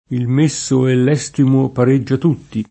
[ $S timo ]